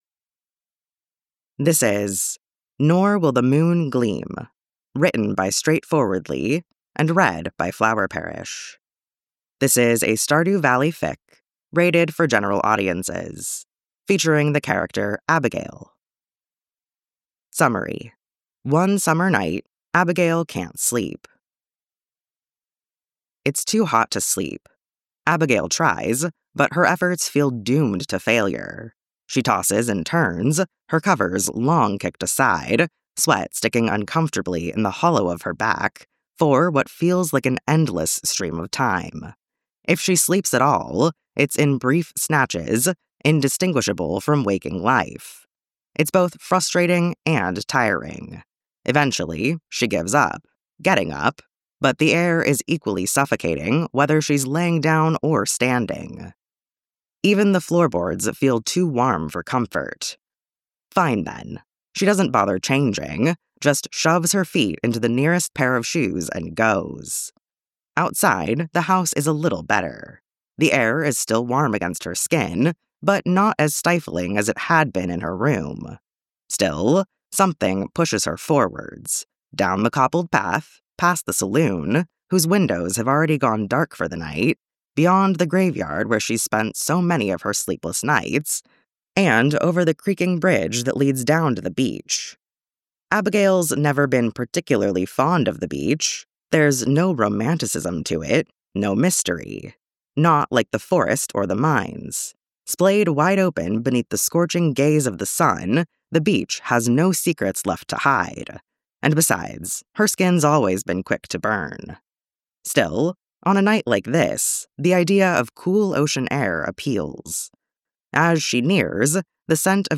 with music: download mp3: here (r-click or press, and 'save link') [3 MB, 00:01:17]